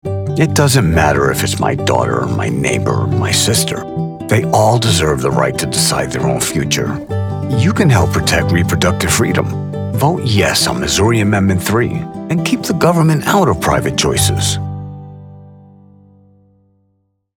Political Voice Over for Democratic Campaigns
Deep, authoritative, and credible — the voice your audience trusts before the message registers.
Custom-built isolation booth, Sennheiser MKH 416, Audient id14mkII interface, Studio One Pro with iZotope RX. Broadcast quality on every take.